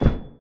Step3.ogg